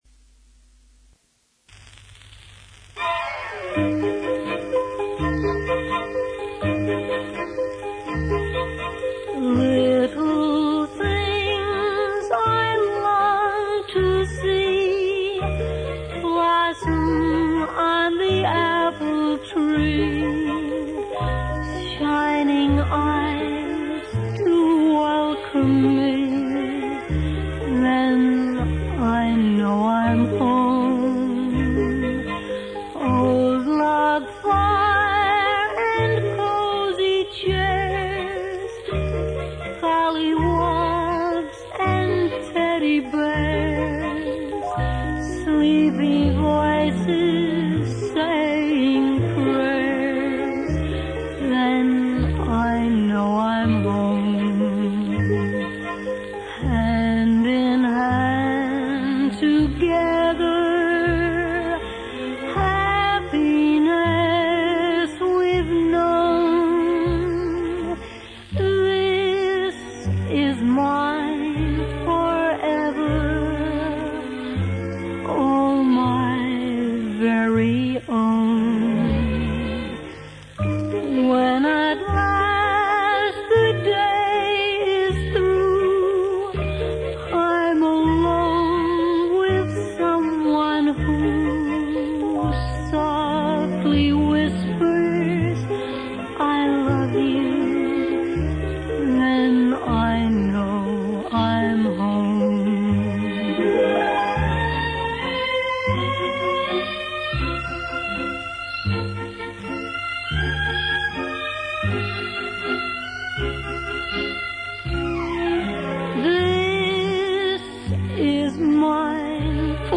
from a 78 rpm record